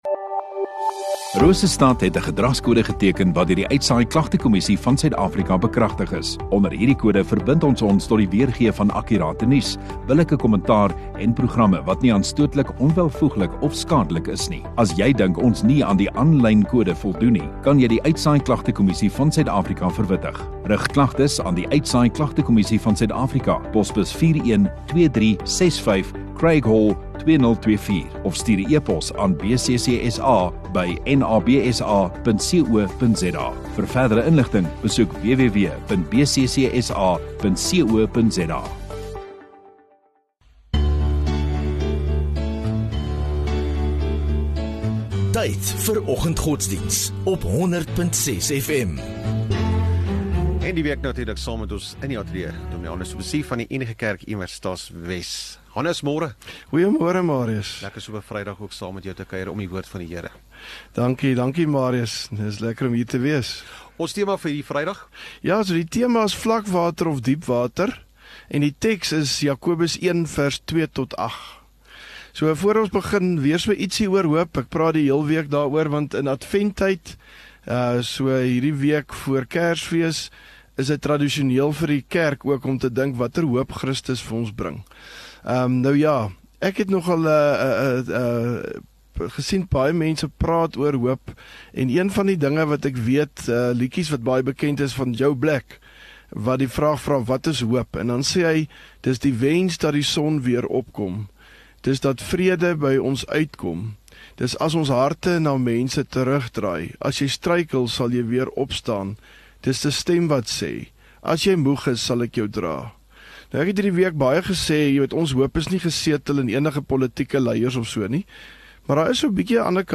6 Dec Vrydag Oggenddiens